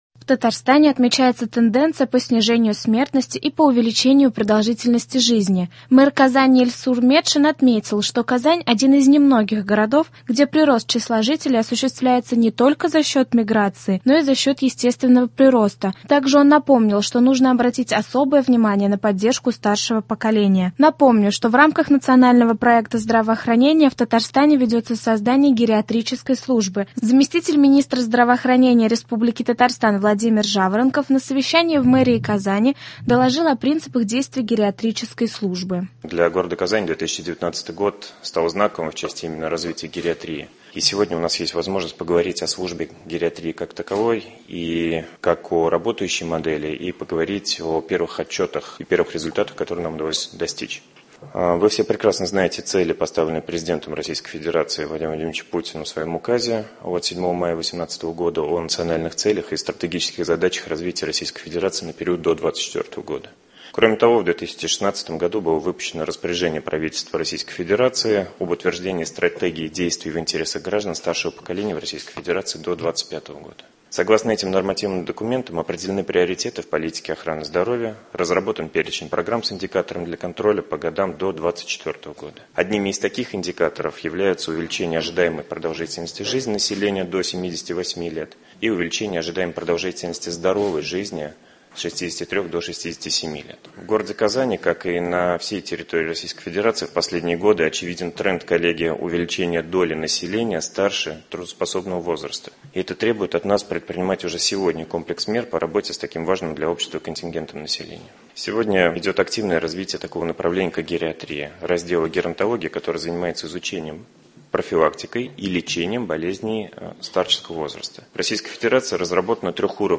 «Актуальное интервью».